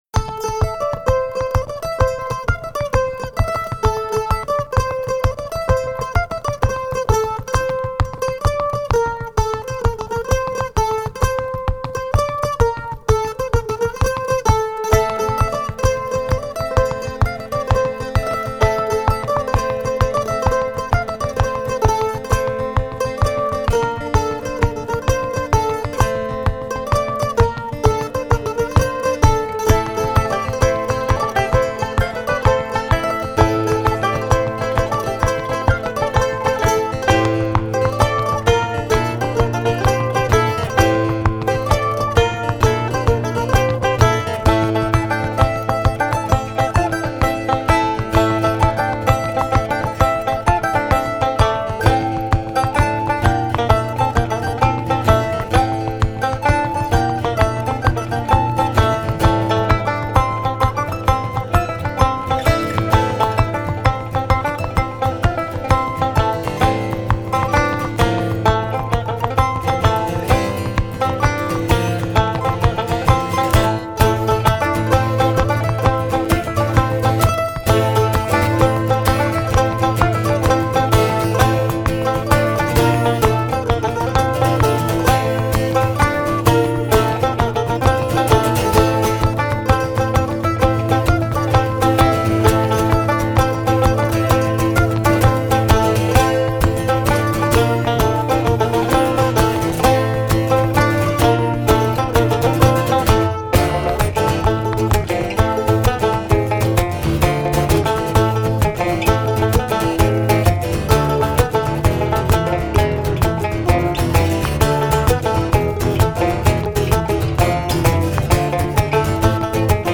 Banjo & Podorythmie, Accordion (feet tapping)
Guitar
Mandolin